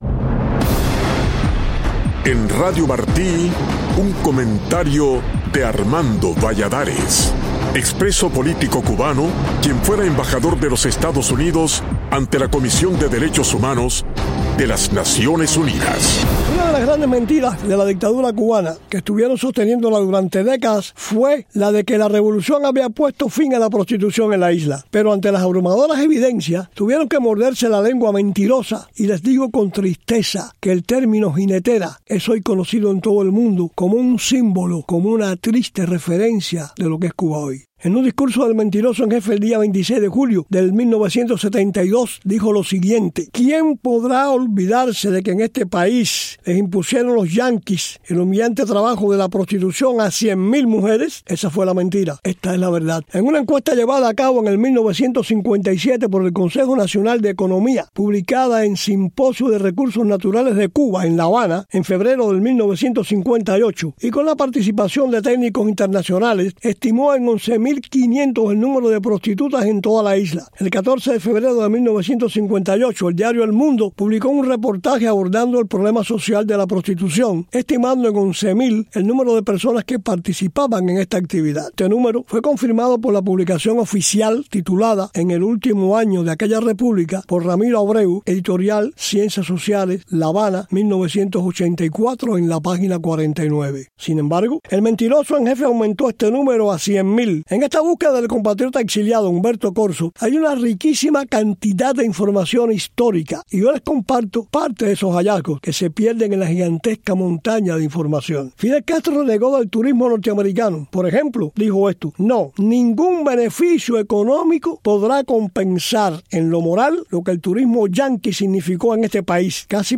Armando Valladares cuestiona en su comentario de hoy las declaraciones del fallecido dictador cubano Fidel Castro, el 26 de julio de 1972, sobre la supuesta imposición de la prostitución por parte de EEUU a más de 100 mil mujeres en la isla.